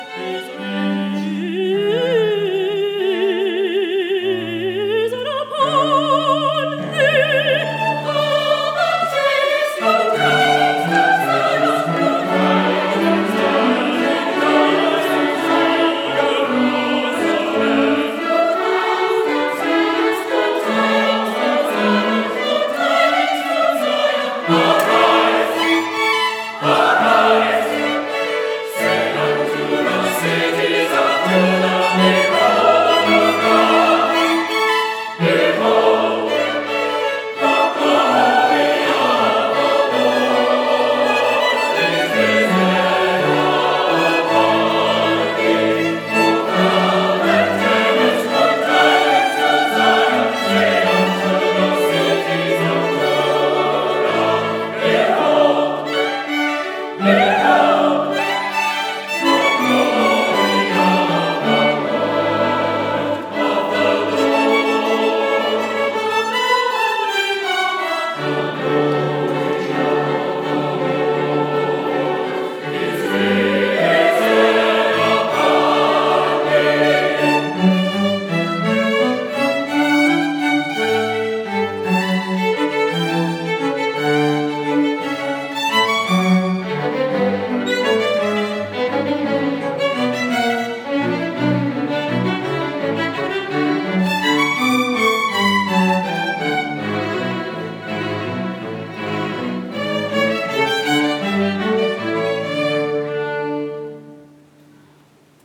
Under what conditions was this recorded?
December 13, 2015 Concert